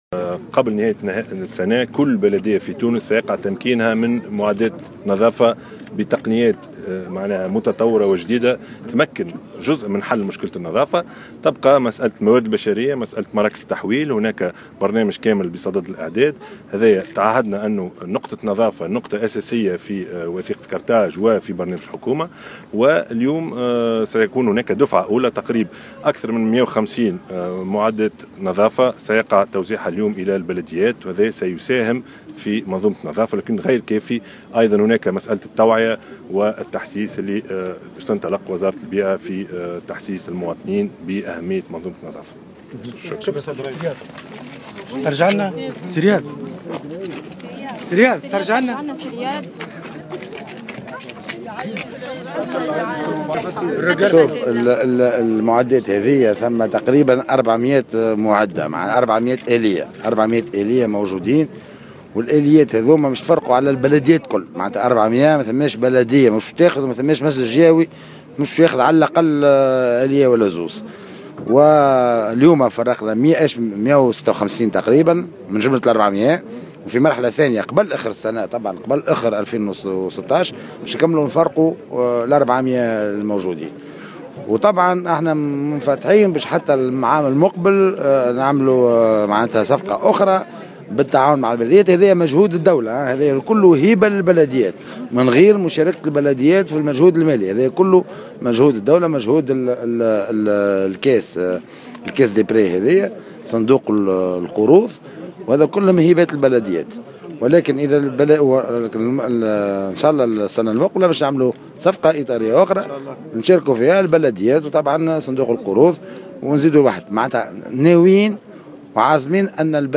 وأضاف في تصريحات صحفية أن هناك برنامجا كاملا بصدد الإعداد يتعلق بمراكز التحويل، مؤكدا أن هناك خطة لإطلاق برنامج توعية لتحسيس المواطنين بأهمية النظافة التي ذكّر بأنها محور أساسي في وثيقة قرطاج.